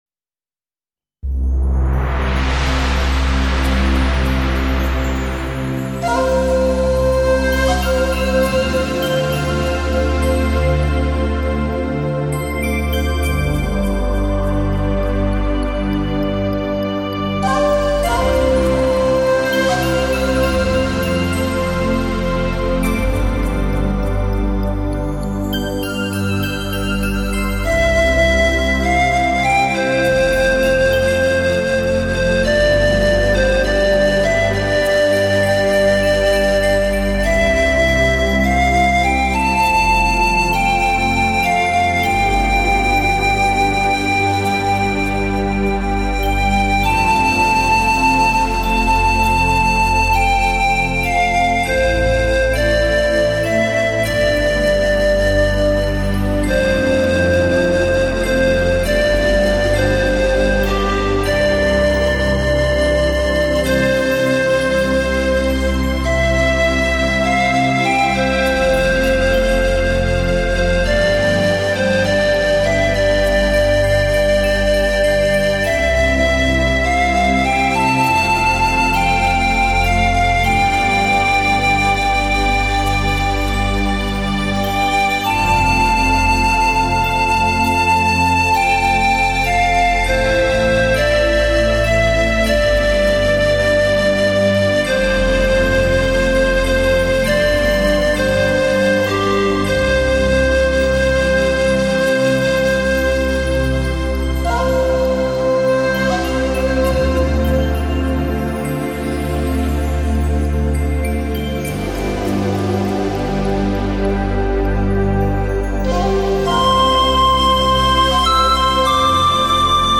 新世纪
营造空灵的新世纪音乐
随着排笛声滑翔在饱满的弦乐中，像置身云间享受漂浮乐趣。